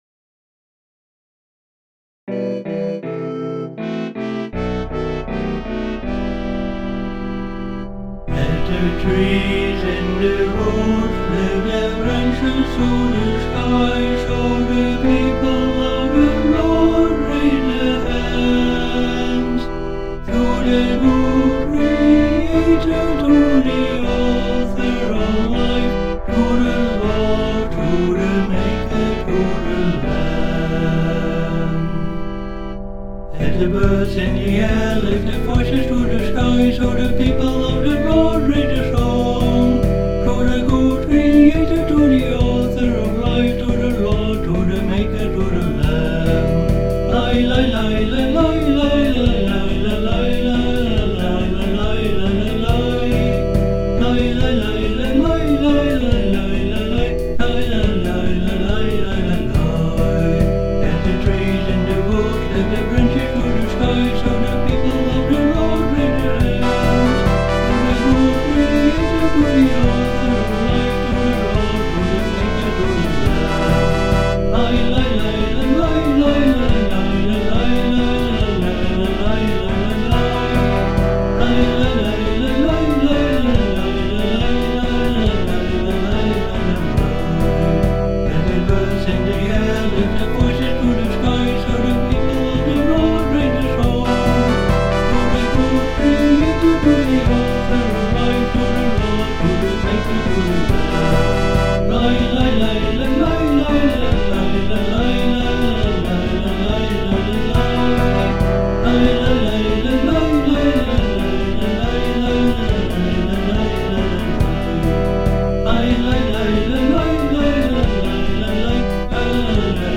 a lively Messianic chorus
Karaoke Video with vocal